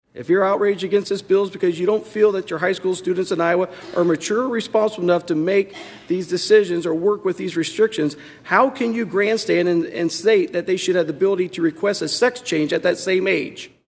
Senator Dickey accused Democrats of being hypocrites.